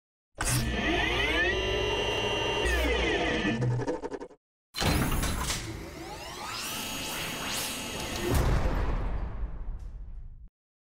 1. Эффект распахивания окна Теневого Бражника n2. Эффект раскрытия окна Теневого Бражника